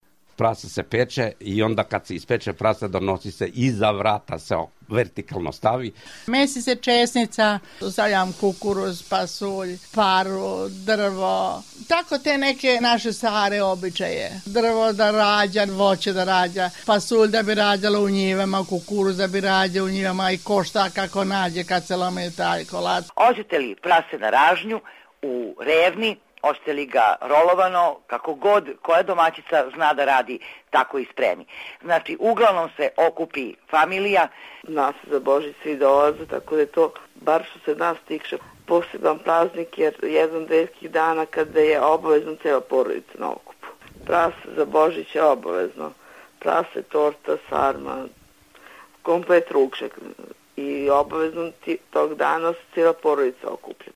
Crkvena zvona